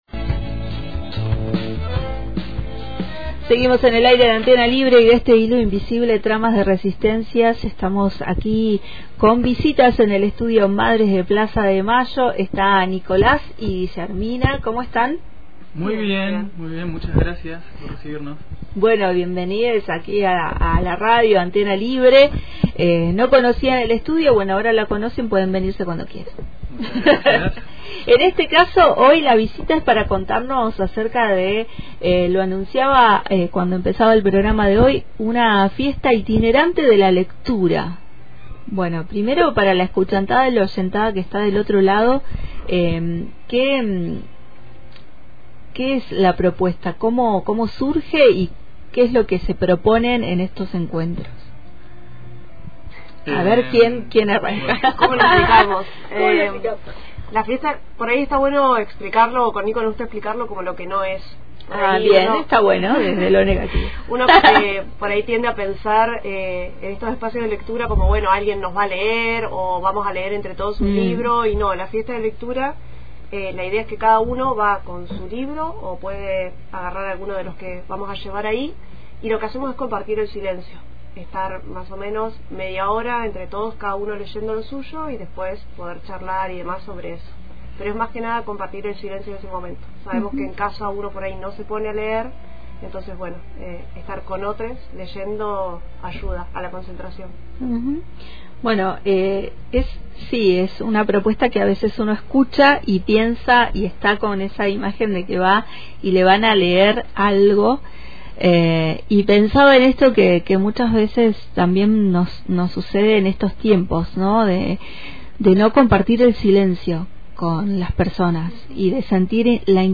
pasaron por el Estudio «Madres de Plaza de Mayo» para invitarnos a participar en la Fiesta de la Lectura